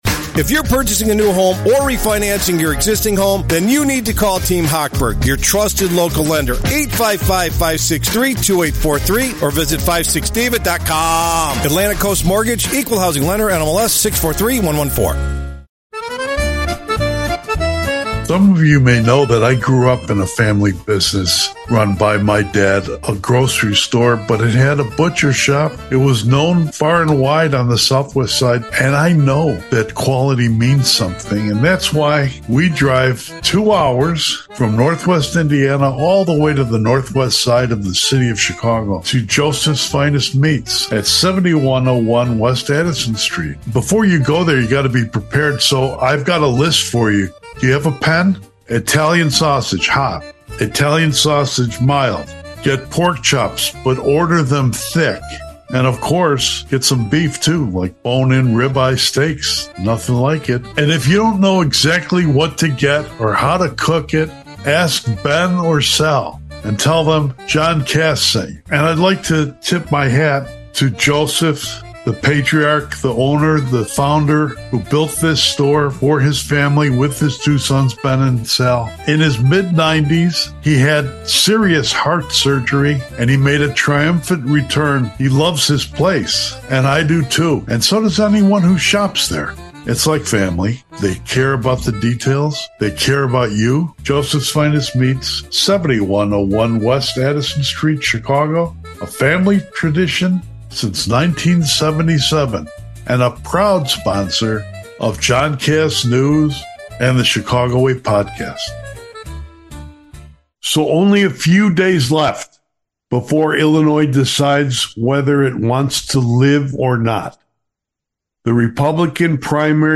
Be a guest on this podcast Language: en-us Genres: News Contact email: Get it Feed URL: Get it iTunes ID: Get it Get all podcast data Listen Now...